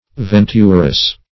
Venturous \Ven"tur*ous\, a. [Aphetic form of OE. aventurous.